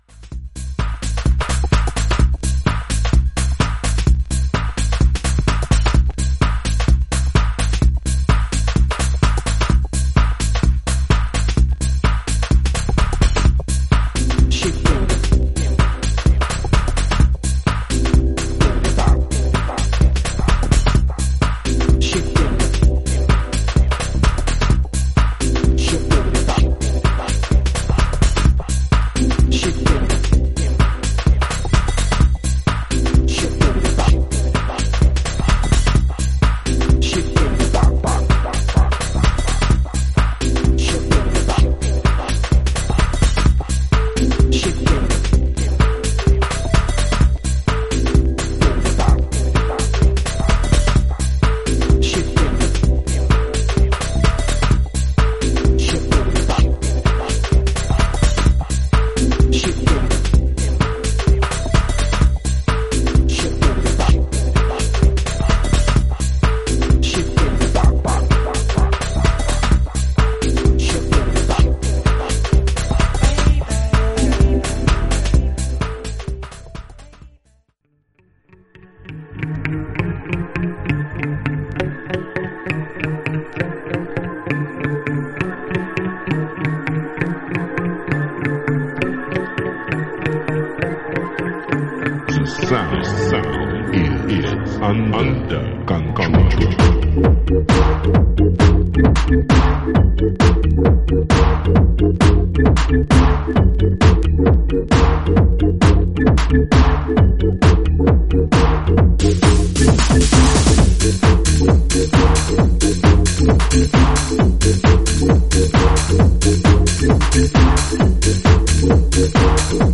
マシン・ファンクなビートとシンセ使いが
バウンシーなビートに絡むフルートのリフや、揺らぎのあるシンセが使いが気持ち良いテック・チューン